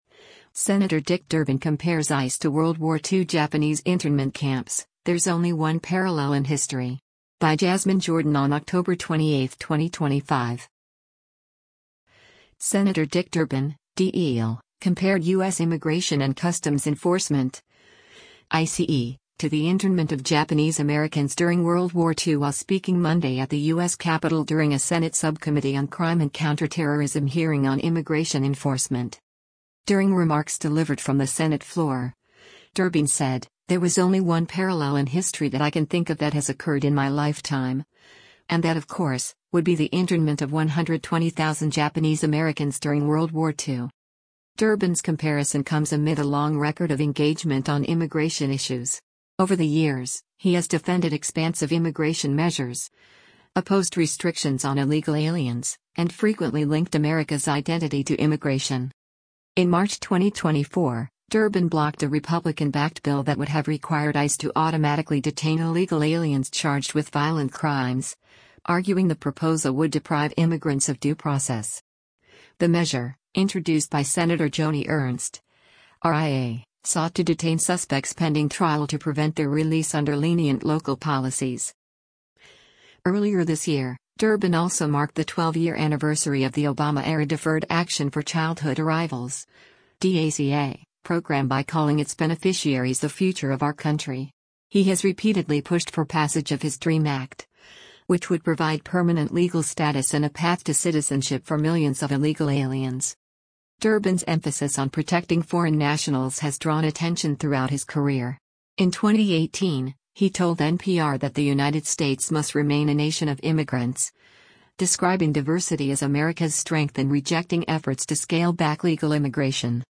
Sen. Dick Durbin (D-IL) compared U.S. Immigration and Customs Enforcement (ICE) to the internment of Japanese Americans during World War II while speaking Monday at the U.S. Capitol during a Senate Subcommittee on Crime and Counterterrorism hearing on immigration enforcement.